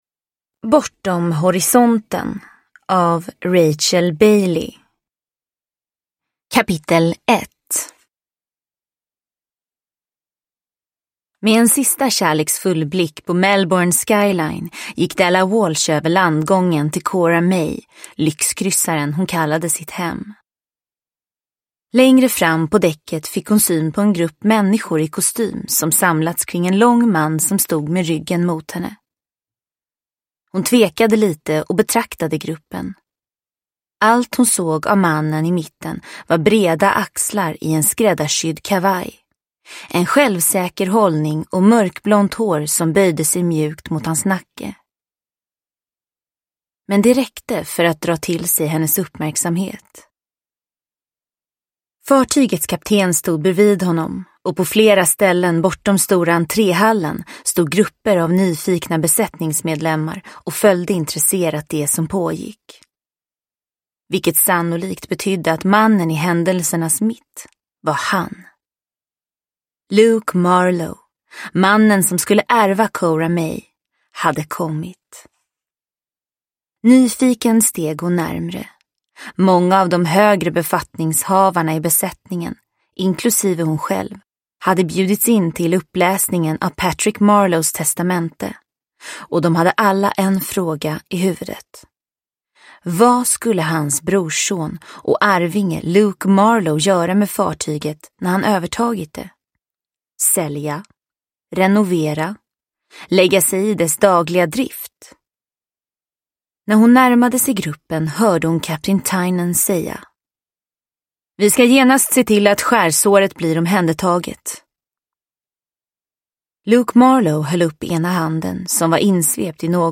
Bortom horisonten – Ljudbok – Laddas ner